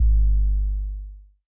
JJCustom808s (9).wav